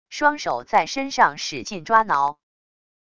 双手在身上使劲抓挠wav音频